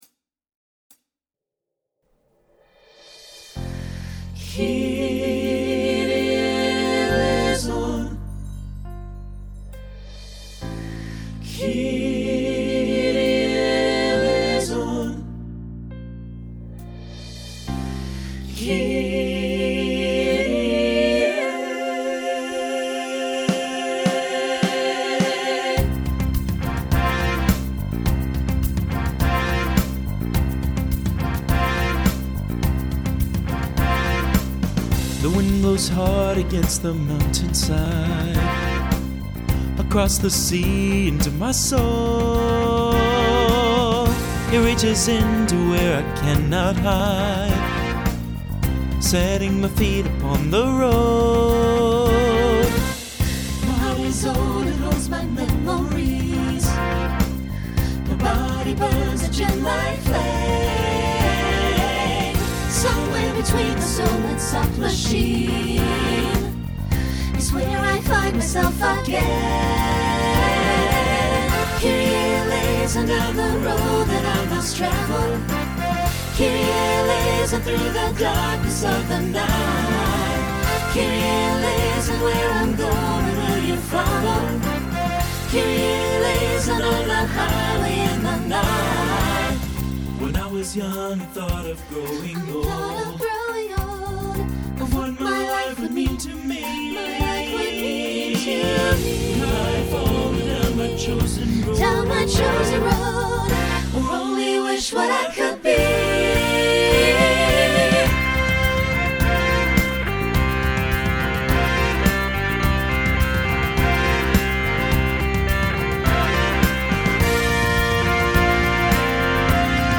Genre Rock
Opener Voicing SATB